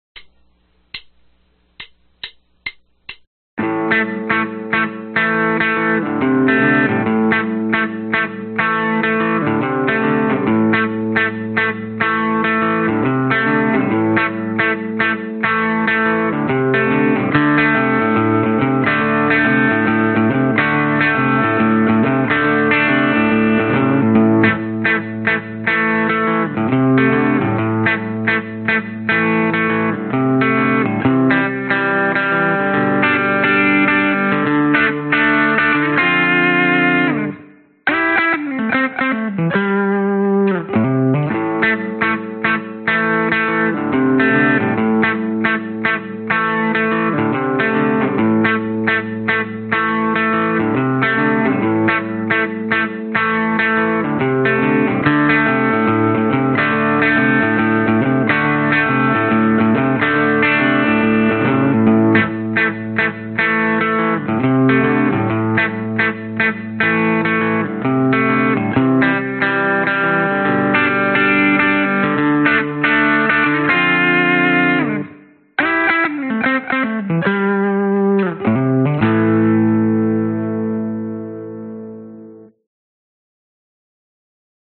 描述：电吉他乡村/布鲁斯(Shuffle)riff140BPM，围绕ADACGDF。
Tag: 蓝调 乡村 吉他 摇滚